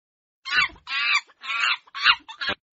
Monkey 128368 (audio/mpeg)